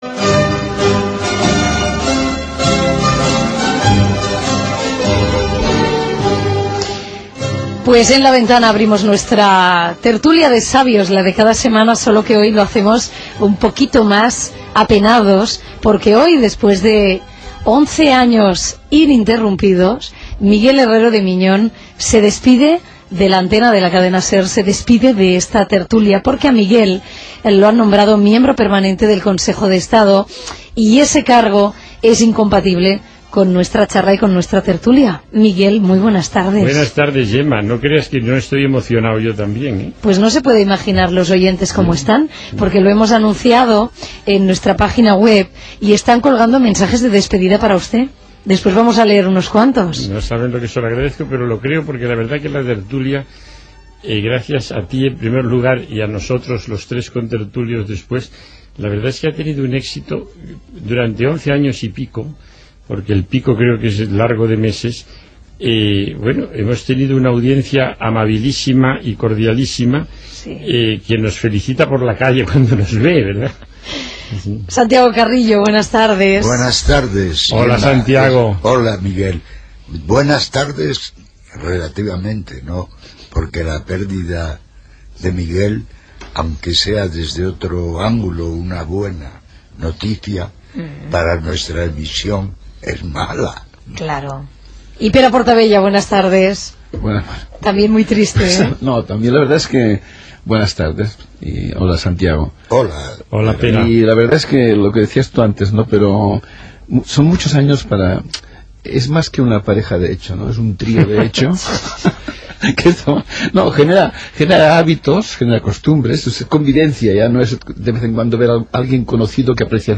15bb7c5362a0e48c3af2d1a3e81388ef769d2a1c.mp3 Títol Cadena SER Emissora Ràdio Barcelona Cadena SER Titularitat Privada estatal Nom programa La ventana Descripció Inici de la secció "Tertulia de sabios". Última intervenció de Miguel Herreo de Miñón en haver estat nomenat membre del Consell d'Estat.